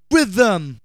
VOX SHORTS-2 0006.wav